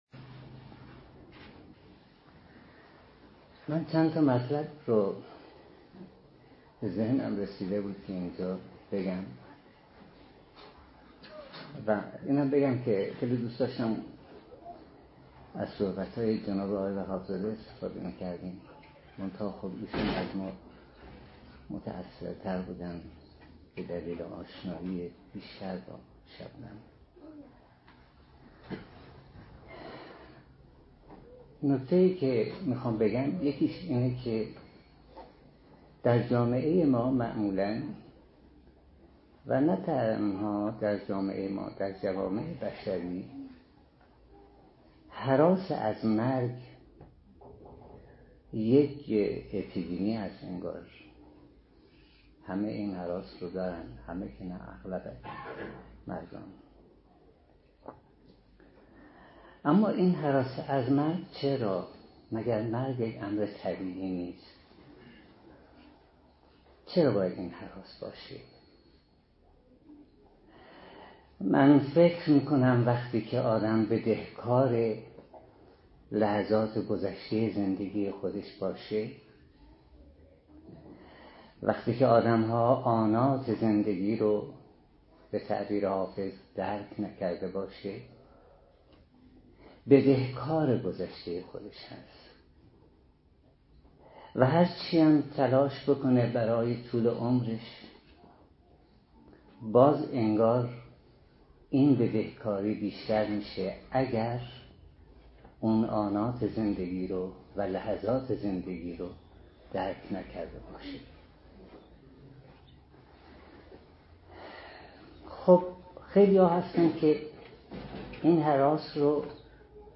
این سخنرانی در مجلسی که به تاریخ 12 آذر ماه 97 در سوگ او در مشهد برگزار شد ایراد شده است